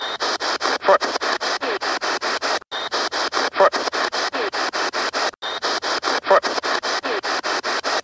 Rose Hill 4 GB #16  -   A few minutes later after some chat,  we get a foul mouth cursing us.